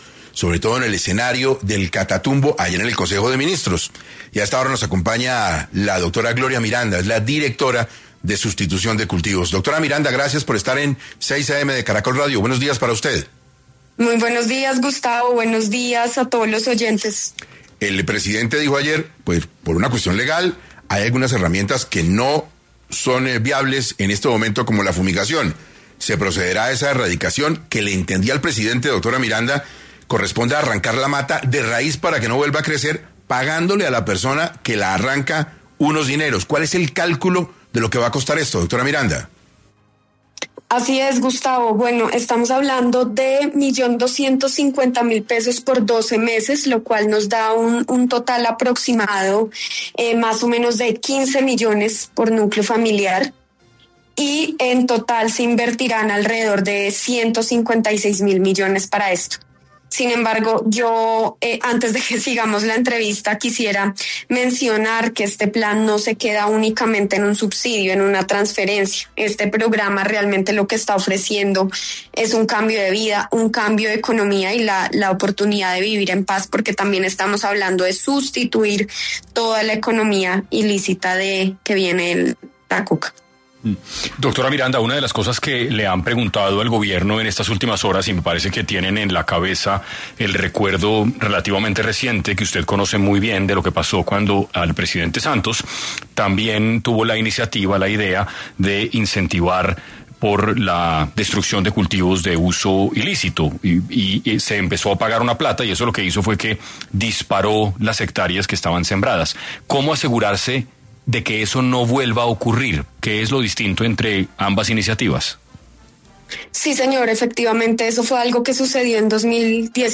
En 6AM de Caracol Radio Gloria Miranda, directora de Sustitución de cultivos de uso ilícito en Colombia, detalló cómo será el plan del gobierno para sustituir los cultivos de coca en el catatumbo